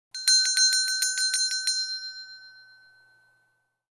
Ring Tone Sound
Notification Sounds / Sound Effects
Hand-bell-slowly-ringing-sound-effect.mp3